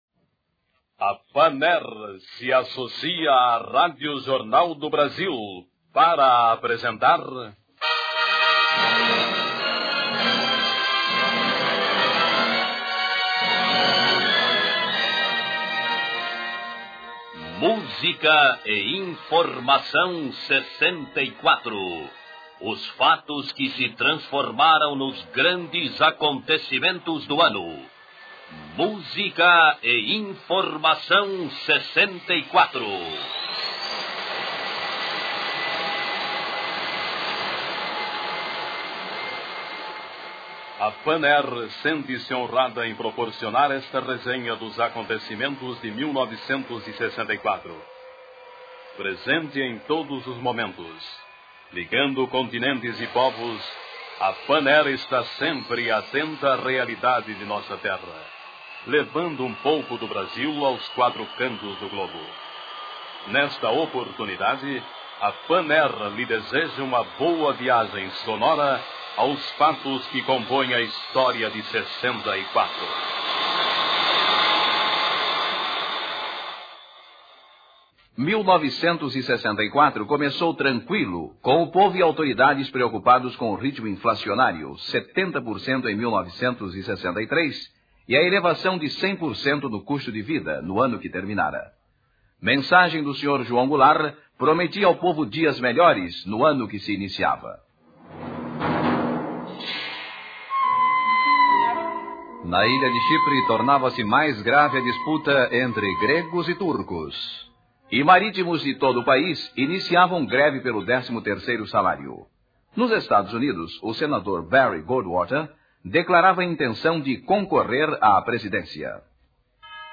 Uma retrospectiva radiofônica de 1964
Música e informação 64: os fatos que se transformaram nos grandes acontecimentos do ano [CLIQUE NA IMAGEM PARA OUVIR O PROGRAMA] A extinta rádio Jornal do Brasil realizava, nas décadas de 1960 e 1970, uma retrospectiva do ano que trazia os eventos marcantes, muitas vezes a reprodução das notícias dadas ao longo do ano, além de músicas, discursos e outras gravações. Patrocinado pela Panair – companhia aérea fundada em 1928 e tornada brasileira após 1948 – o programa Música e informação 1964 traz o registro radiofônico da escalada de eventos que levou à deposição do presidente João Goulart em março, e o desenrolar dos acontecimentos até dezembro.